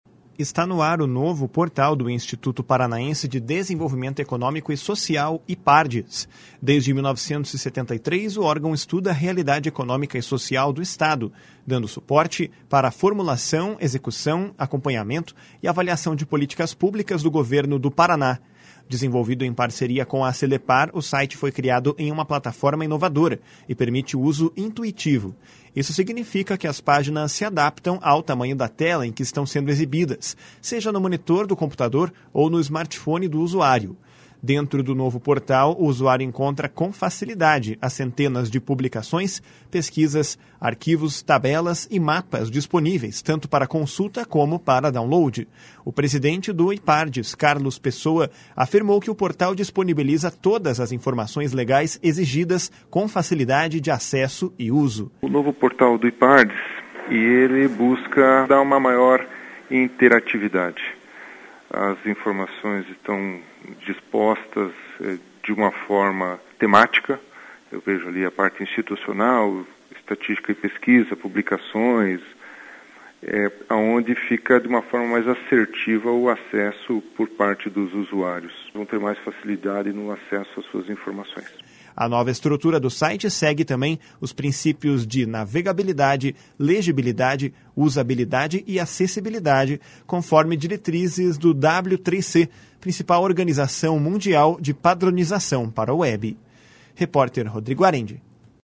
O presidente do Ipardes, Carlos Pessoa, afirmou que o portal disponibiliza todas as informações legais exigidas com facilidade de acesso e uso.